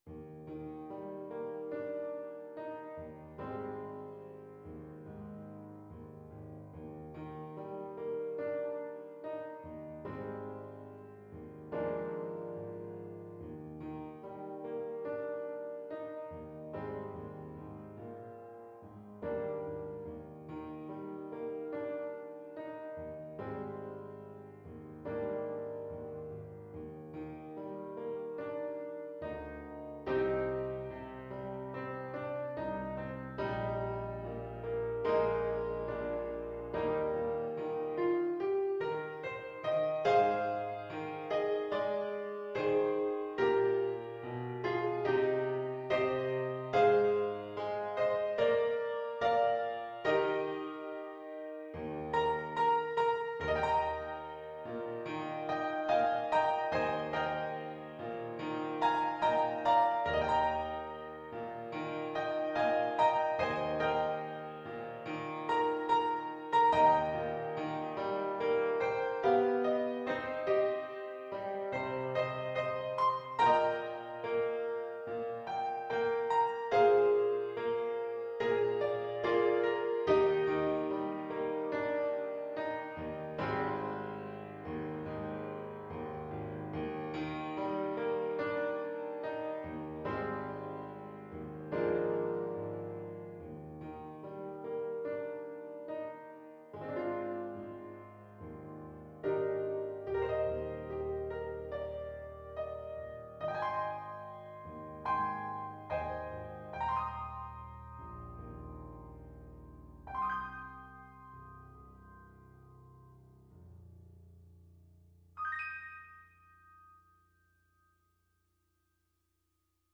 4/4 (View more 4/4 Music)
Molto espressivo =c.72